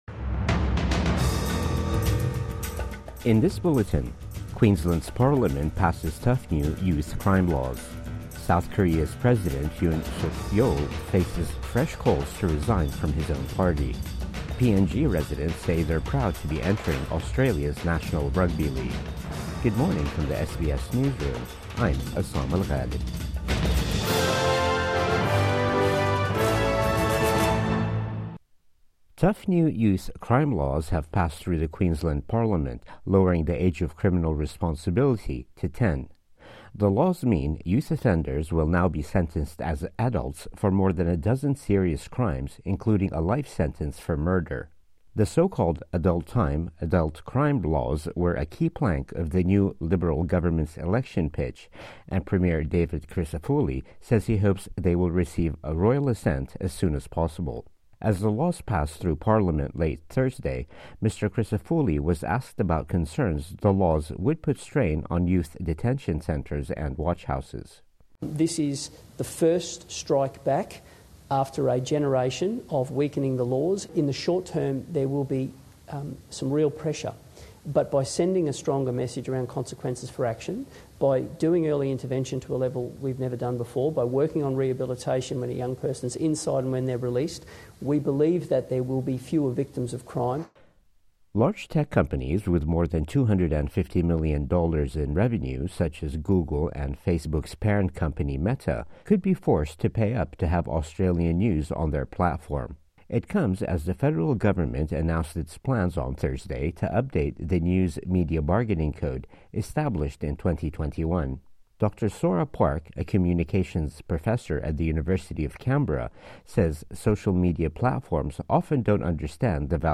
Morning News Bulletin 13 December 2024